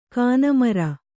♪ kāna mara